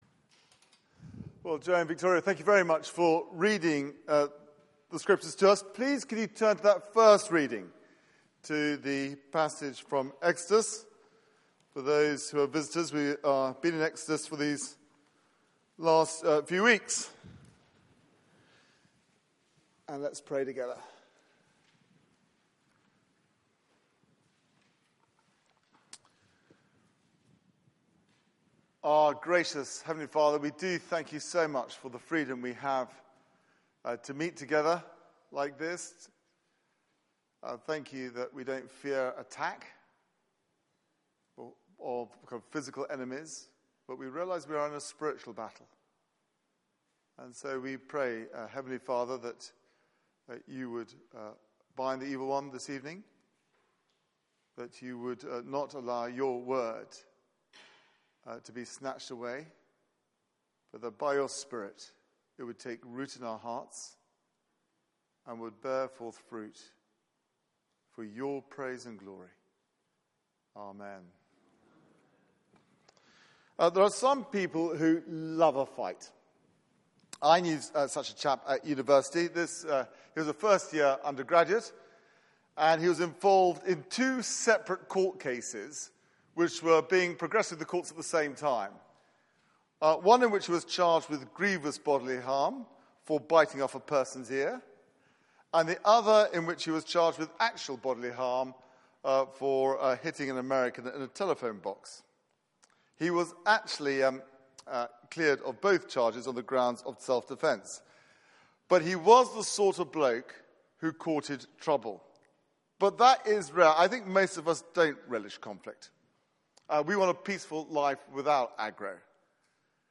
Passage: Exodus 17:8-16 Service Type: Weekly Service at 4pm